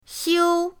xiu1.mp3